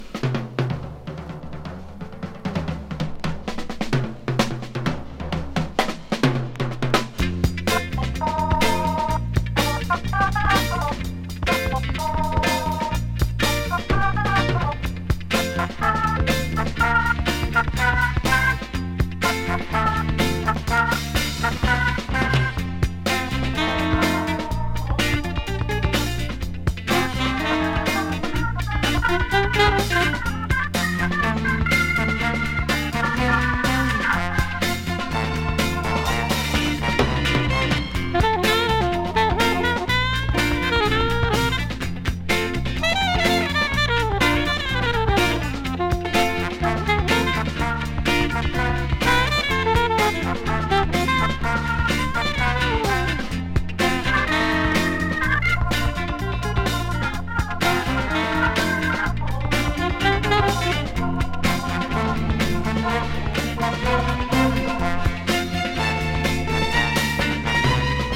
グルーヴィー・インスト・オルガンファンク